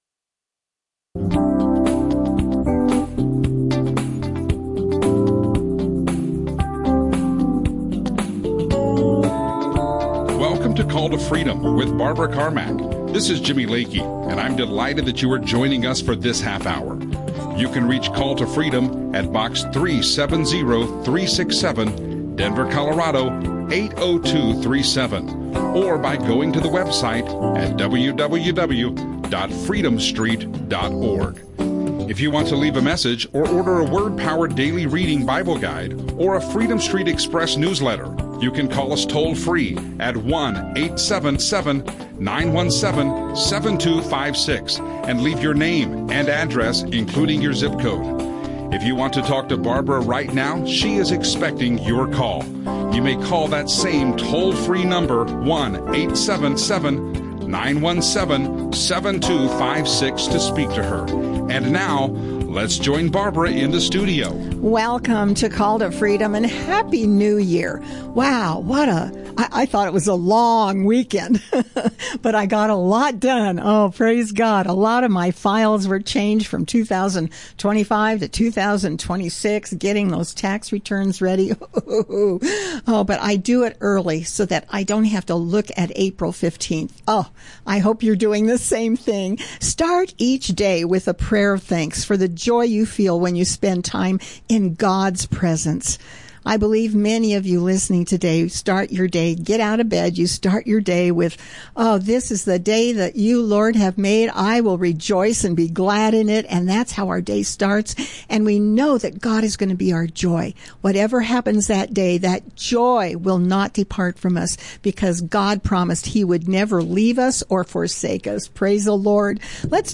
Christian radio